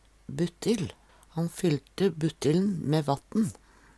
buttill - Numedalsmål (en-US)